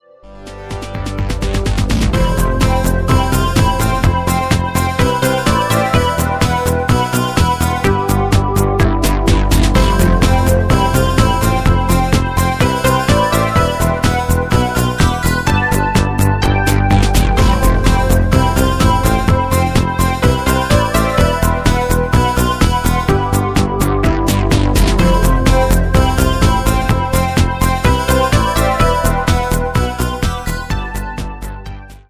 Здесь, кроме мелодической линии сплошь Monomachine.